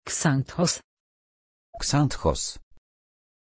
Pronunția numelui Xanthos
pronunciation-xanthos-pl.mp3